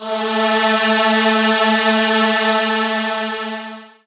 voiTTE64001voicesyn-A.wav